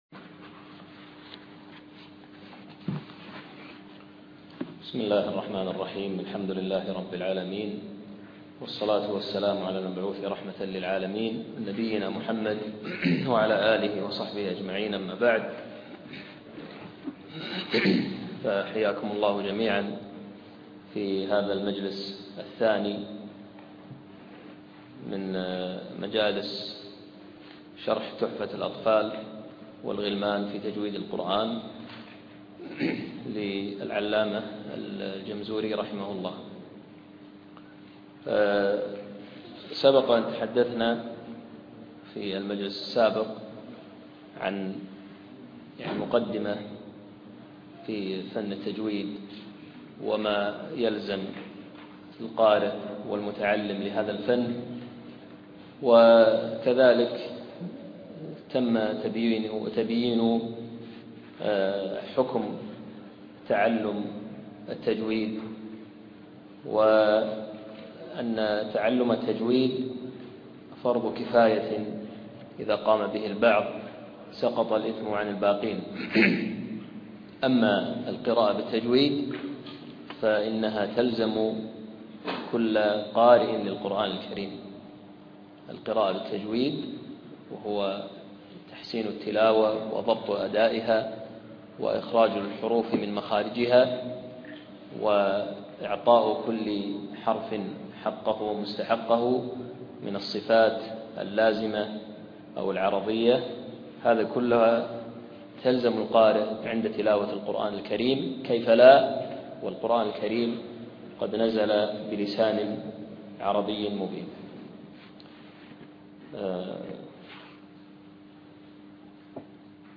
أقيمت الدورة شهر 8 عام 2014 في مسجد الحمادي في منطقة المهبولة بدولة الكويت
الدرس الثاني